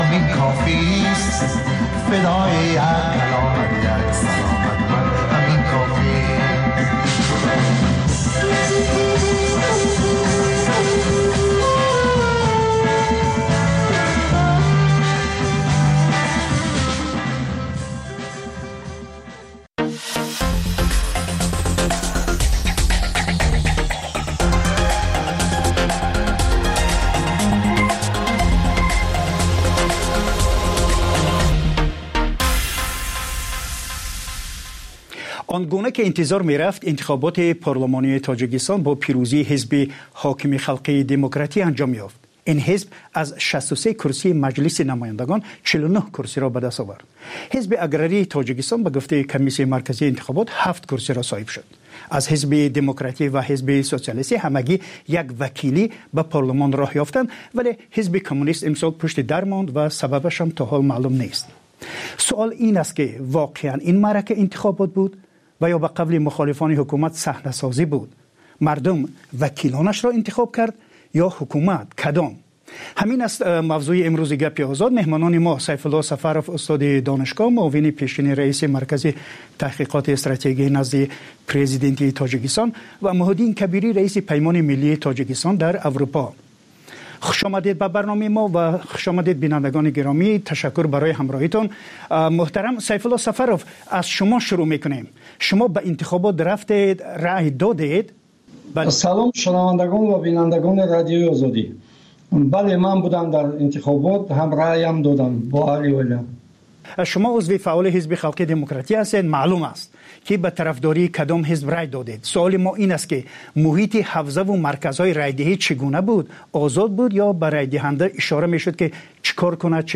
Маҷаллаи хабарӣ